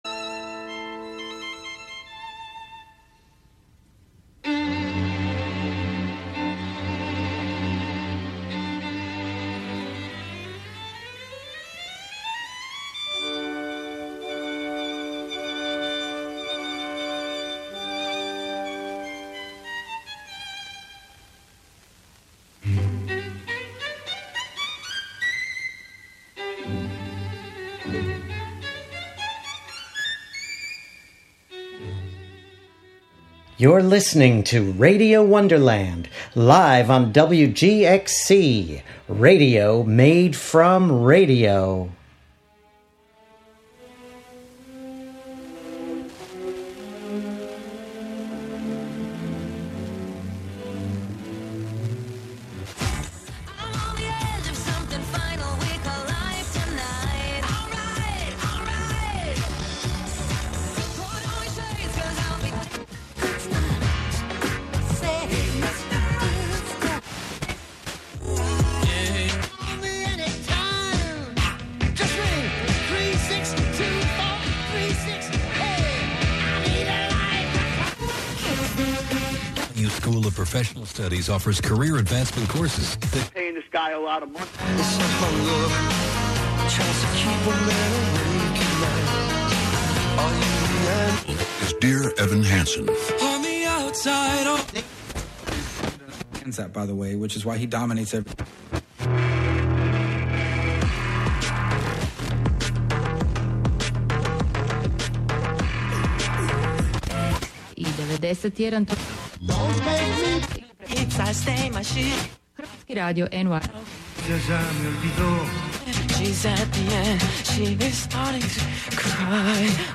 Live from Brooklyn, NY